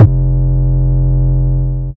Index of /Antidote Advent/Drums - 808 Kicks
808 Kicks 15 F.wav